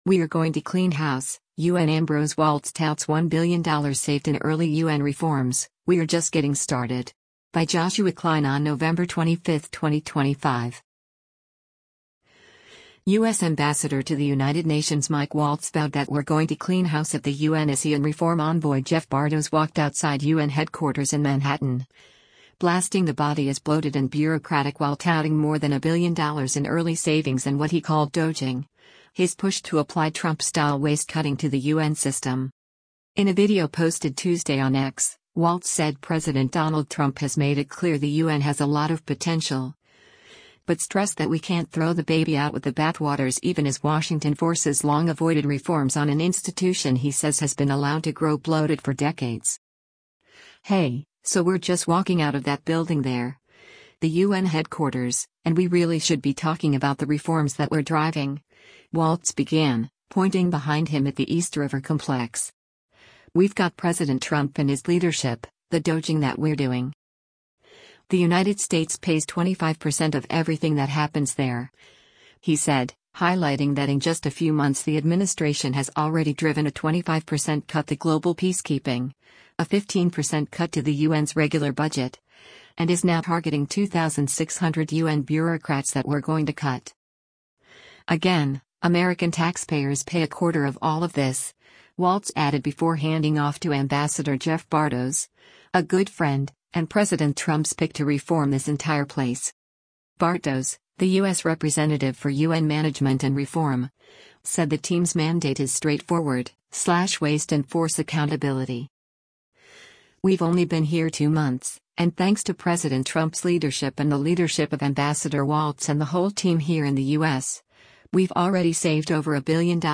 Mike Waltz interview